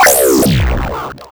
respawn.wav